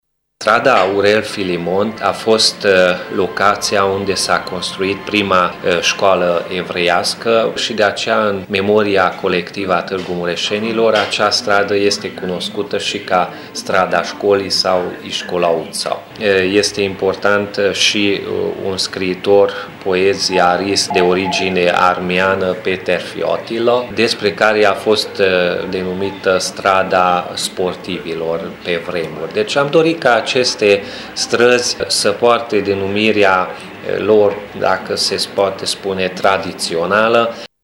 Viceprimarul municipiului Peti Andras: